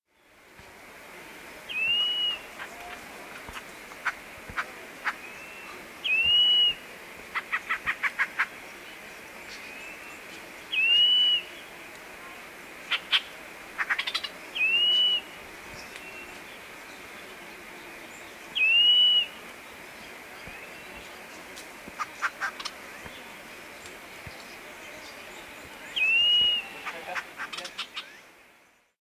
birdsounds